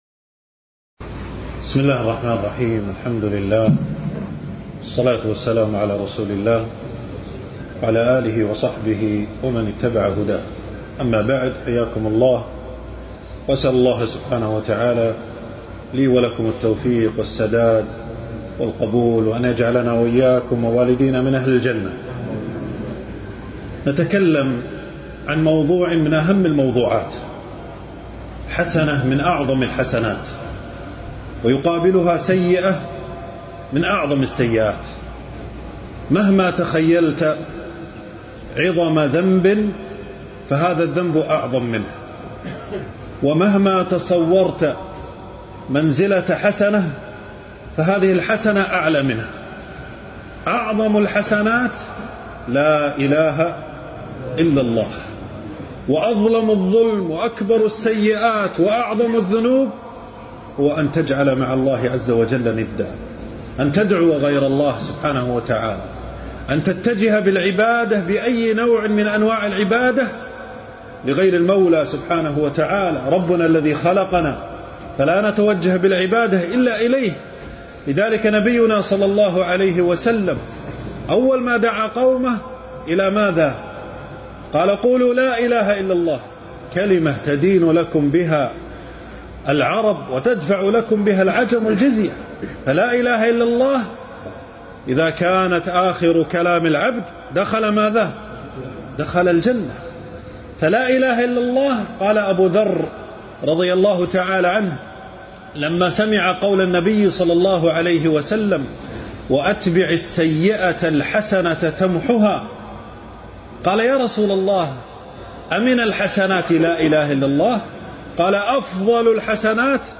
تنزيل تنزيل التفريغ محاضرة بعنوان
في مسجد محمد العيار.